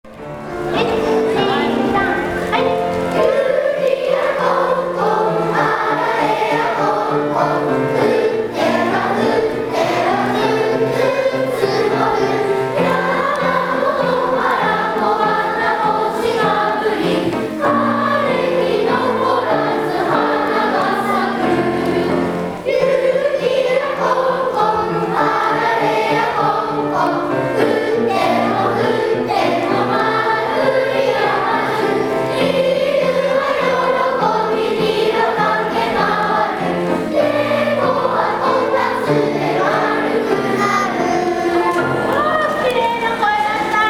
創立４９周年記念芸術鑑賞会